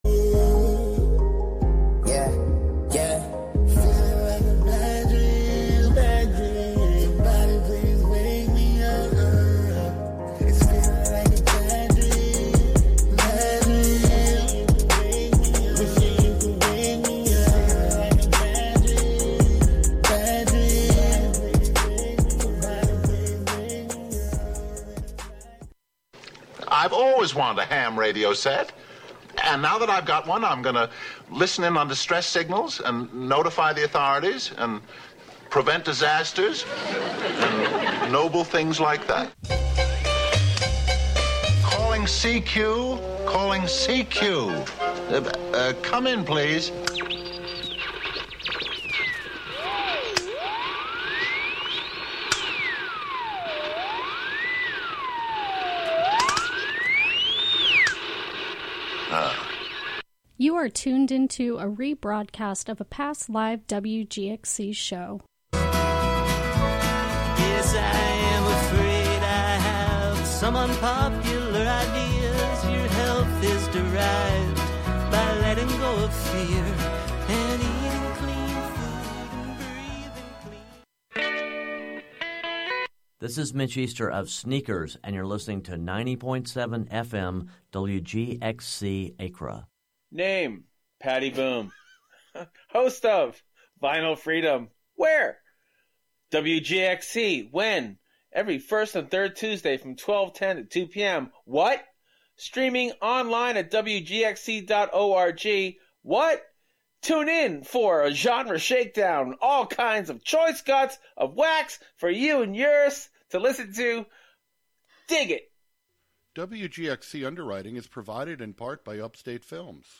It also amplifies Indigenous music traditions to bring attention to their right to a sustainable future in the face of continued violence and oppression. We will explore the intersection of acoustic ecology, musical ethnography, soundscape studies, and restorative listening practices from the perspective of Indigenous musical TEK (Traditional Ecological Knowledge).